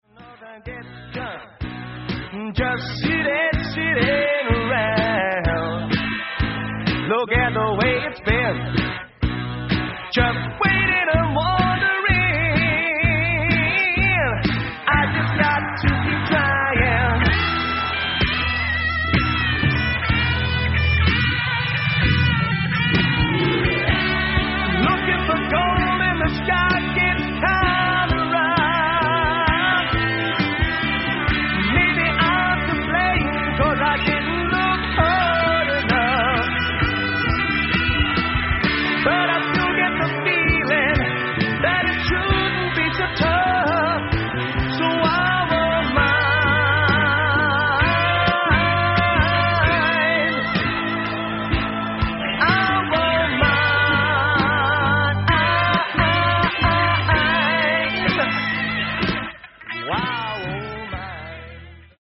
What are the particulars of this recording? Musicland / Munich, Germany